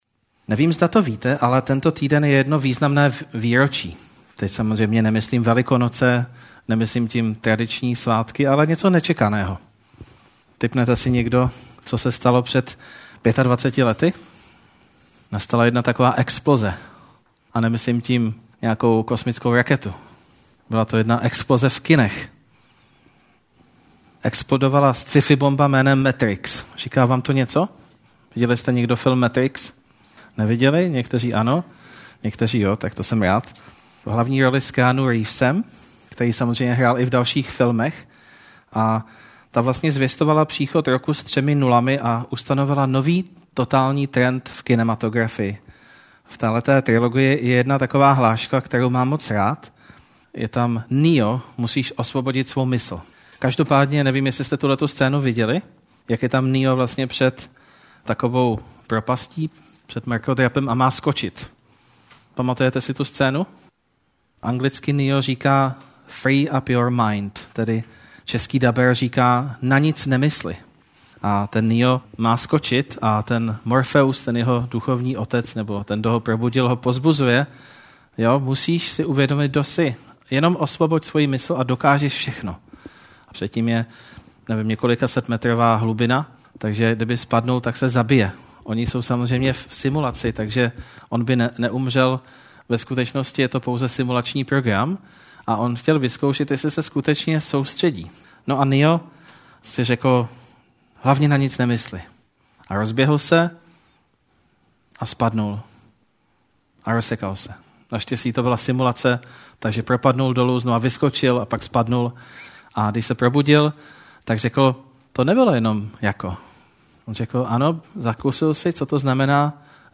hlavní text ke kázání: 1.list Petrův 1:13-21
Kategorie: Nedělní bohoslužby